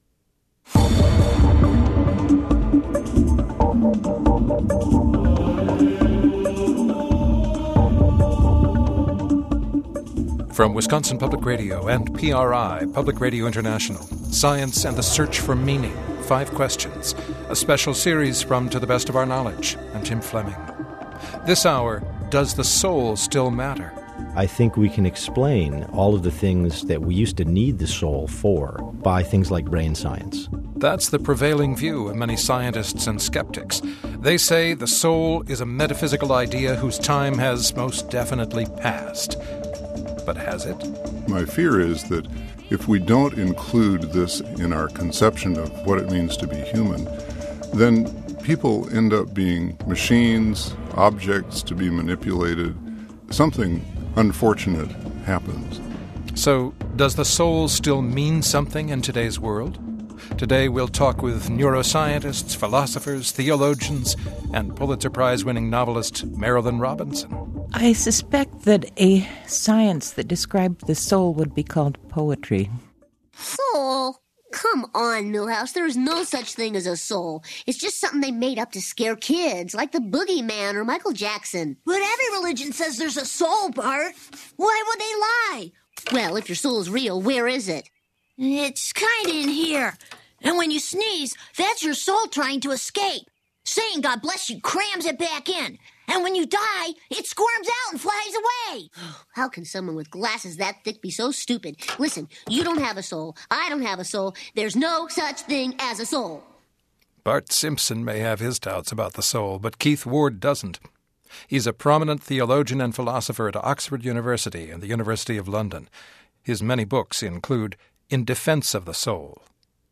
You've got to listen to Elna Baker's reading. It's a little cutesy, but she, a New Yorker, a Mormon, has a way of disarming you, even the most ardent skeptic out there.